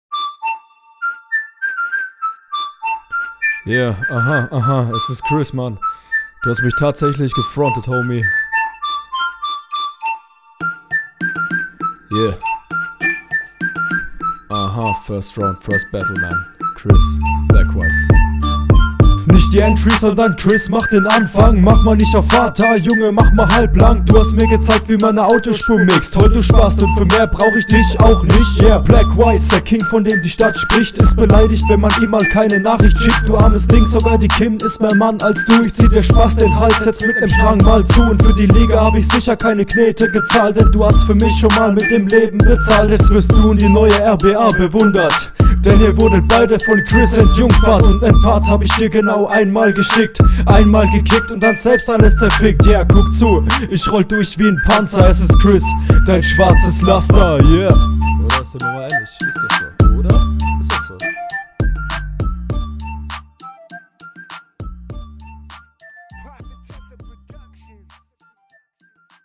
Solide aber Monoton, die Stimmfarbe ist gleichbleibend.